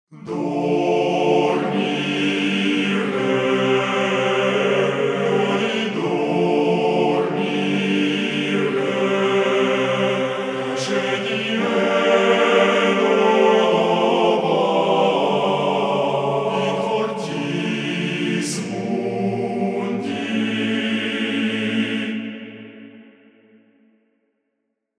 Знаю что пишу уже почти через год, но если всё же кому то интересно, делал пробы из 24 записей себя, результат в файле. 3 партии в каждой по 8 человек. Писал на 2 разных микрофона и питчил.